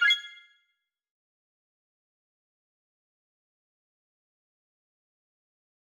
confirm_style_4_005.wav